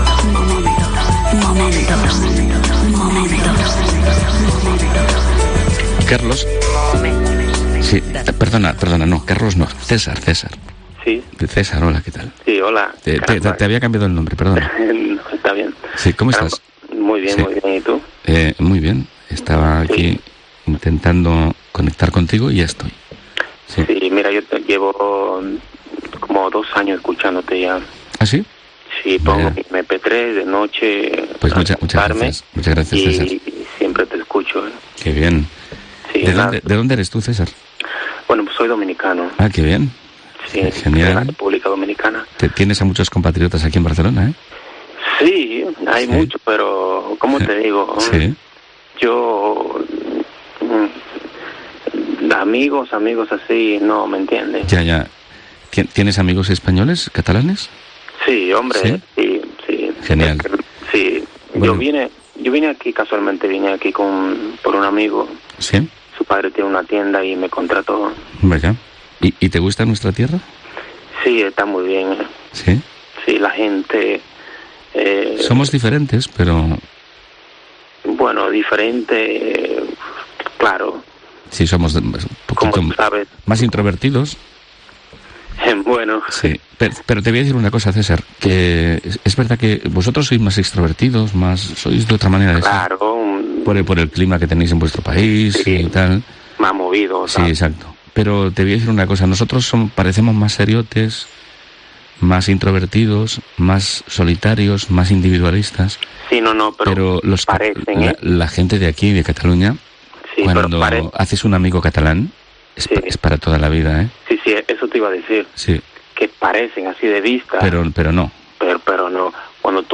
'MOMENTOS' Radio de madrugada